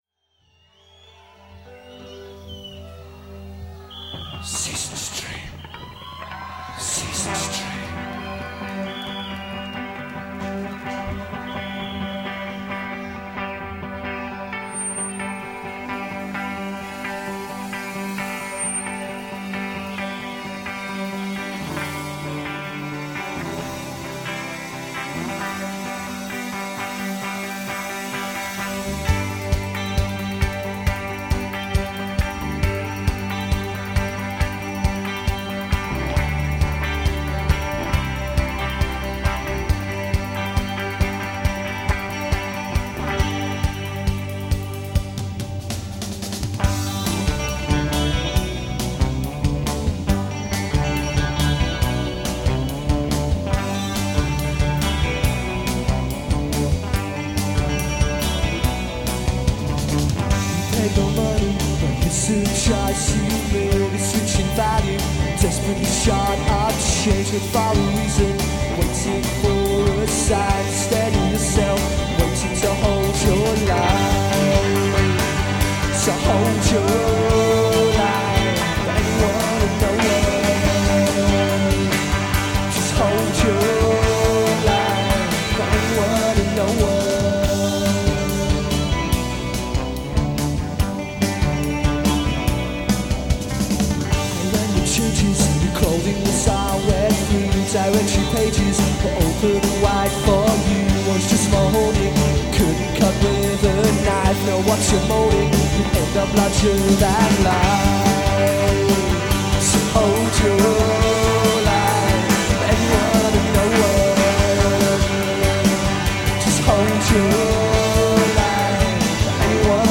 was recorded at Glasto on June 27th, 1992